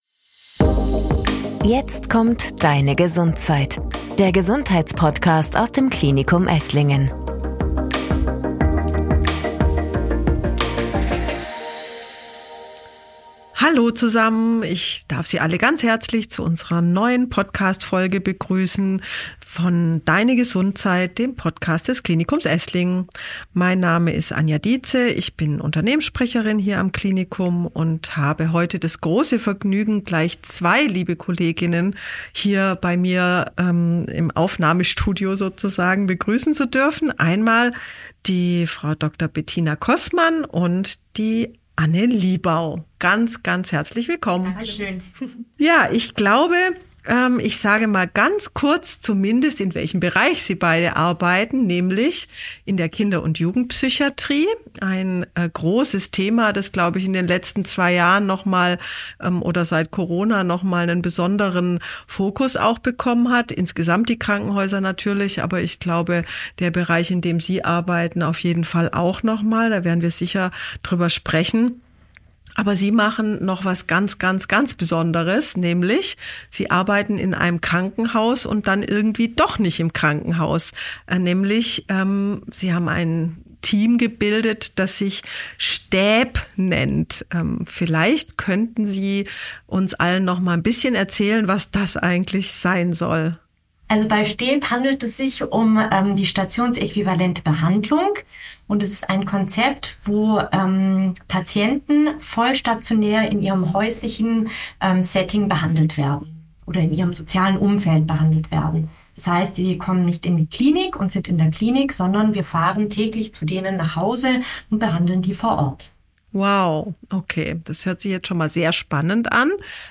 Stationsäquivalente Behandlung – ein Gespräch mit